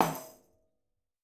TC2 Perc15.wav